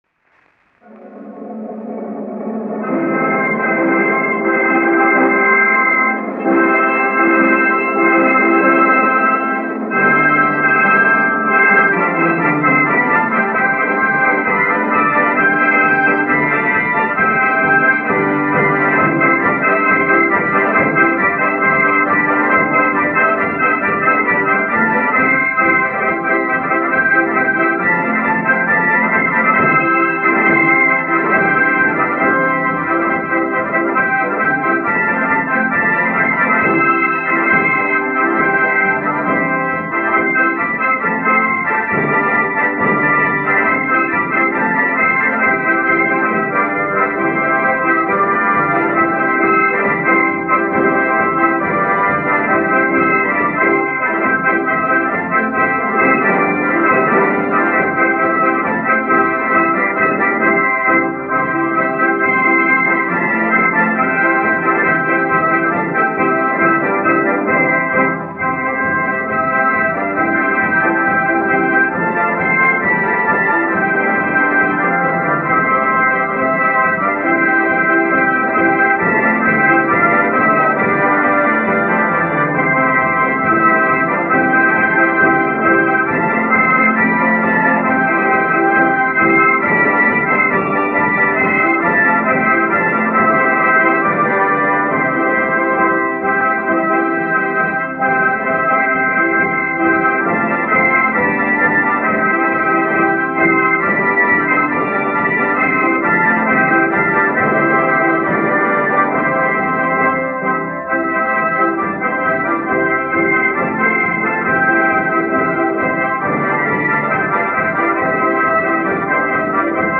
Scots Moothie
Recording 15. is The Silver City Harmonica Band playing an American Medley.